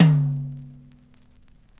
Dusty Tom 03.wav